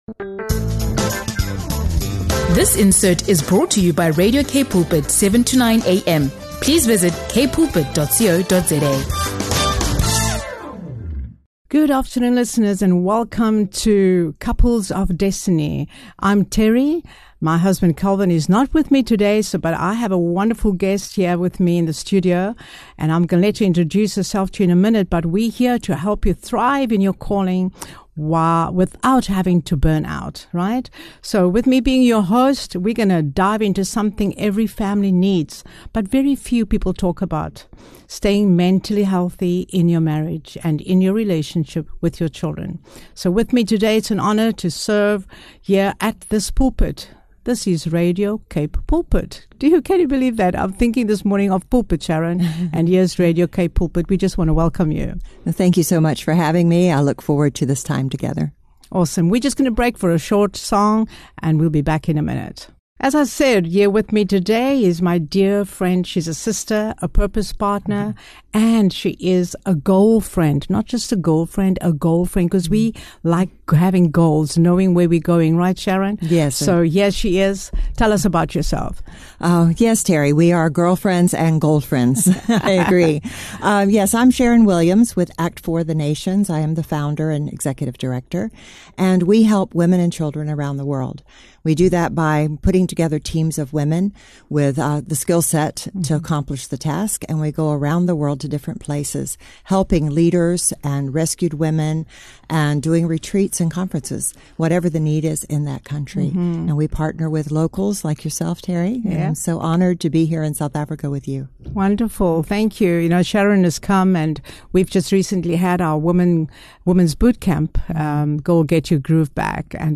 They discuss practical ways to balance ministry, parenting, and marriage without burnout, emphasizing the importance of open communication, setting boundaries, and including children in ministry so they feel valued and connected. From modeling faith to your children, scheduling family time, and navigating travel and outreach, this conversation provides actionable insights for couples to maintain emotional and spiritual health while serving God.